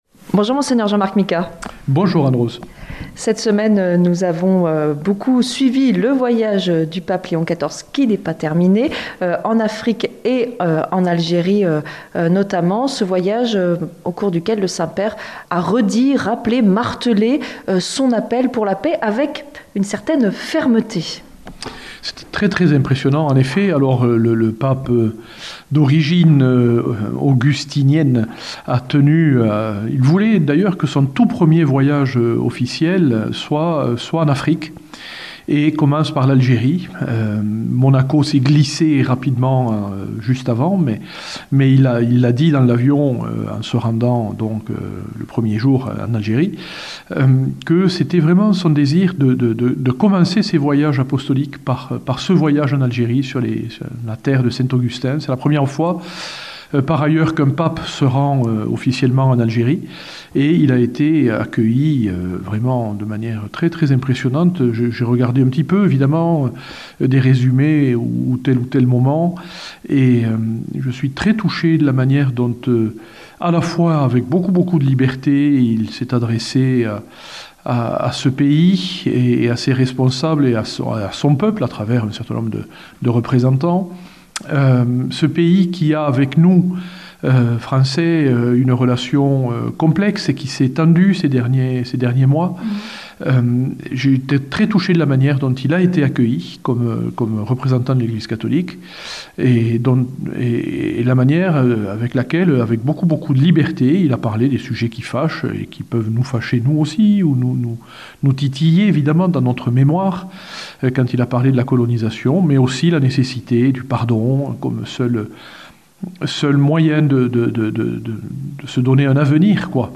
Entretien avec Mgr Micas - Évêque de Tarbes Lourdes